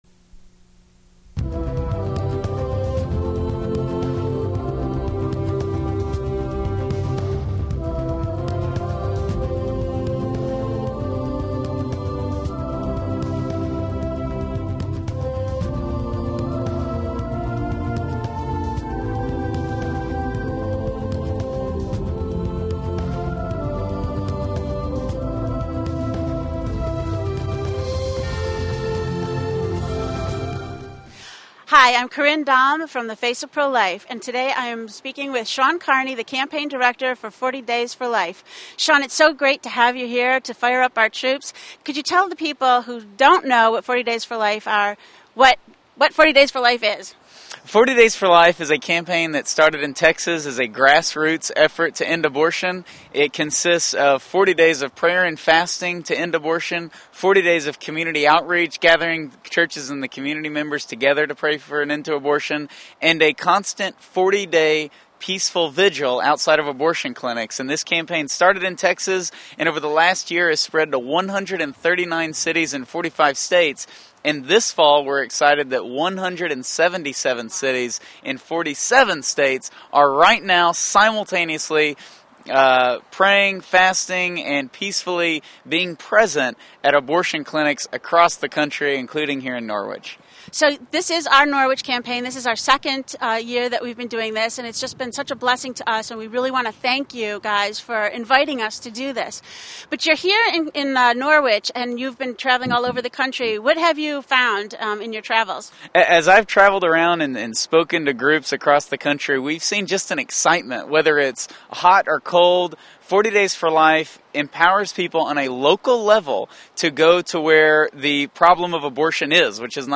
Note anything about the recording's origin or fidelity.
dropped into the Abortion Mill at 12 Case St Norwich to fire up the troops and give them some encouragement as we near the